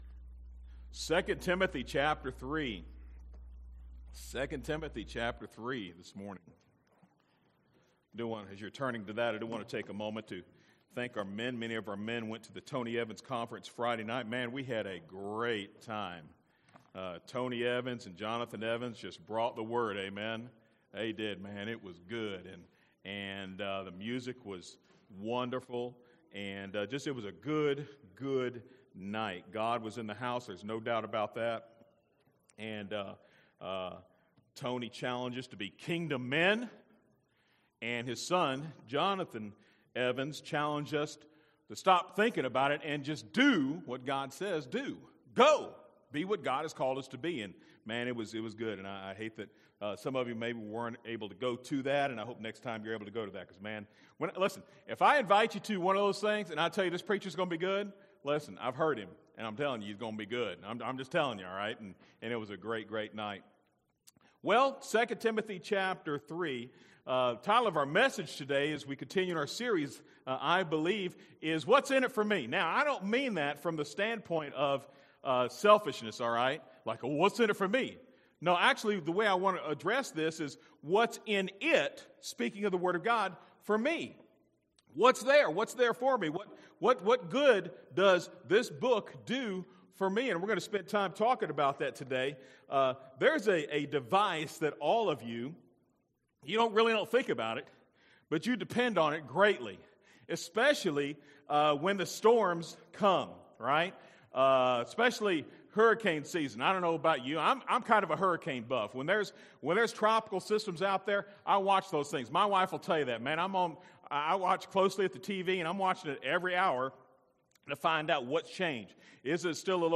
A message from the series